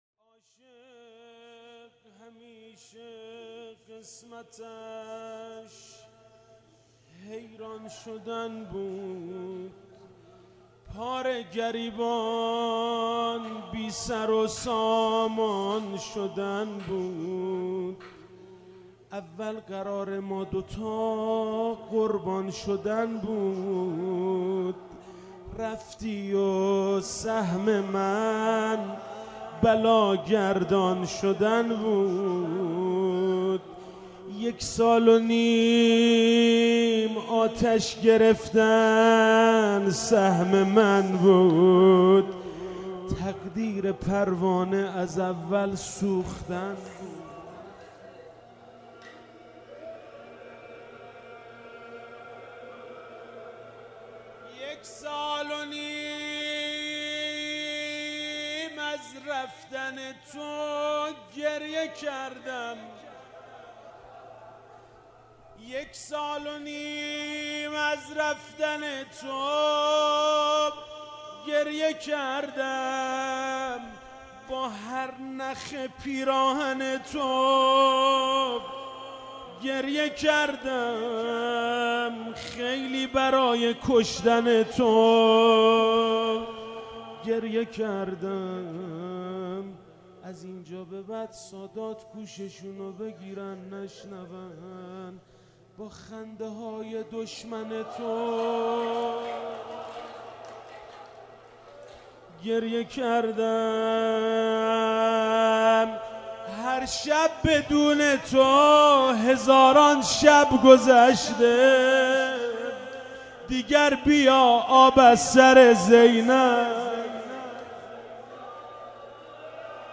شهادت حضرت زینب (س) 97 - روضه - عاشق همیشه قسمتش حیران شدن بود